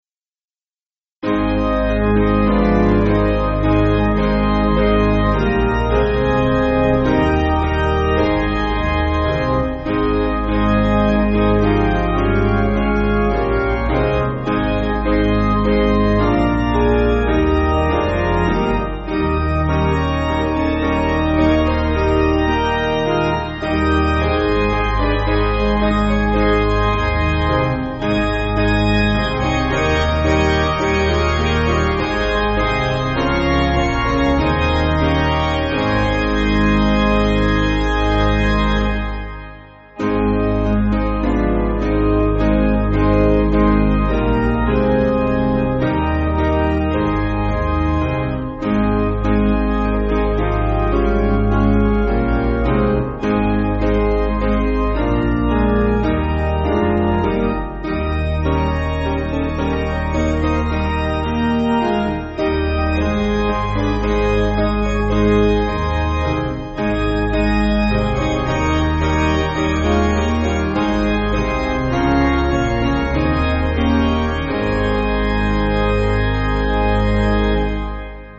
Basic Piano & Organ
(CM)   4/Ab